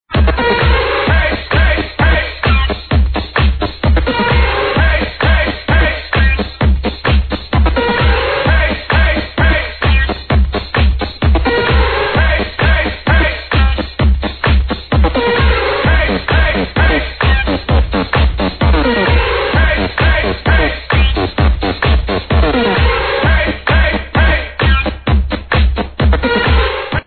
house tune title needed